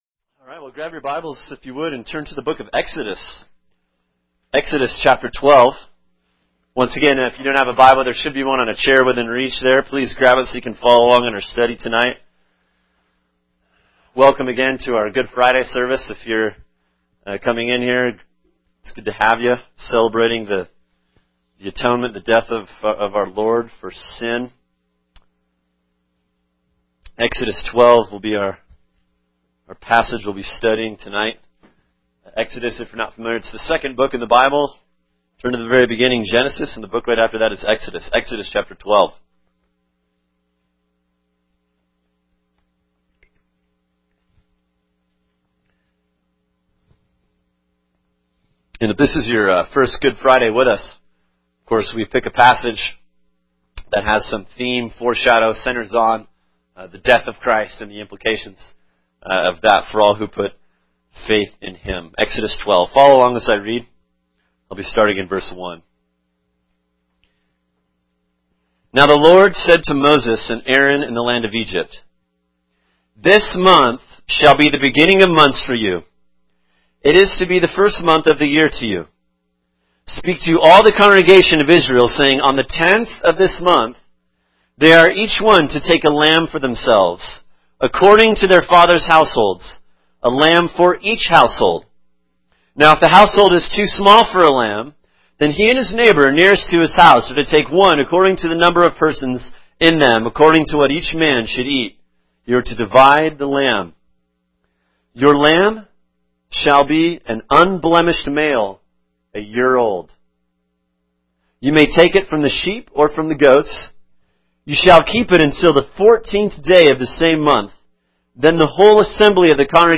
[sermon] Exodus 12 “Safety Under the Blood” | Cornerstone Church - Jackson Hole
2013 Good Friday